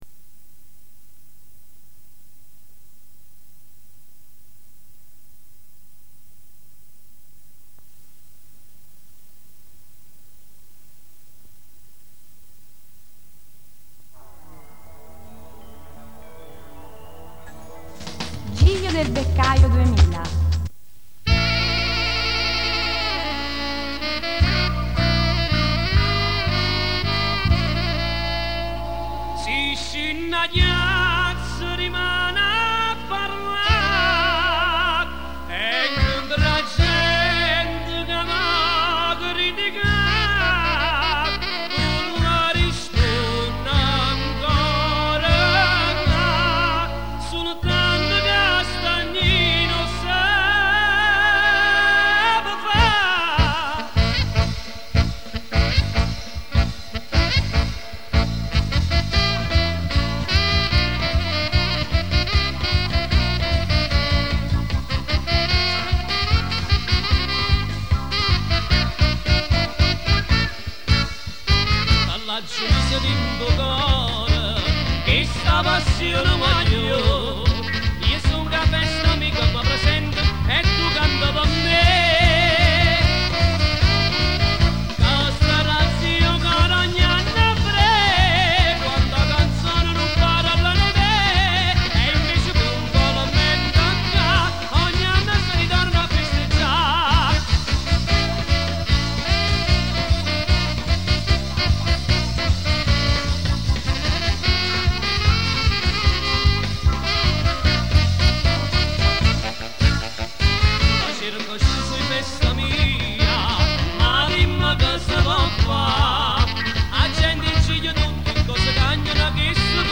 versione live